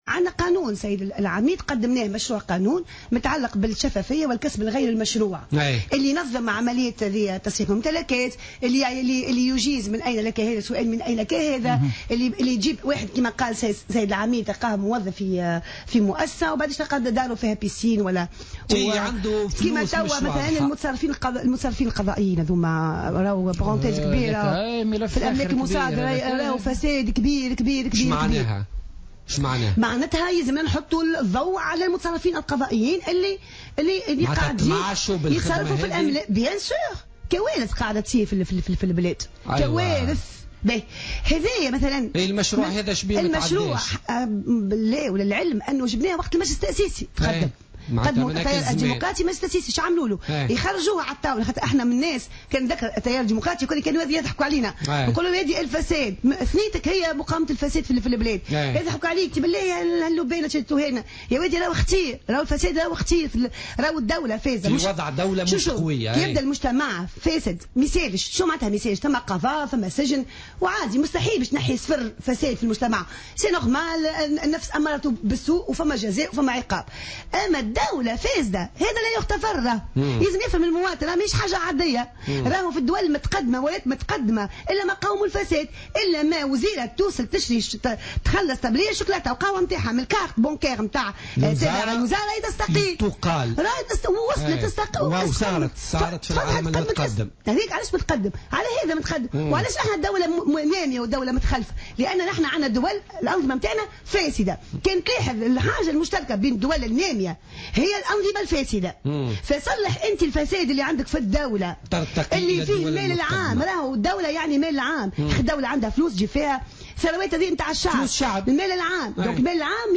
وحذرت النائبة، ضيفة حصة اليوم الخميس من بوليتيكا، من الفساد الموجود في الدولة التونسية والذي اعتبرت أنه أخطر من الفساد في المجتمع، بما يسببه من إهدار للمال العام، إلا أن الدولة ترفض اعتبار مكافحة الفساد كأولوية والسعي من أجل تفكيك منظومة الفساد التي تنخر اقتصاد البلاد، وتتسبب في خسارة تقارب النقطتين في نسبة النمو السنوي.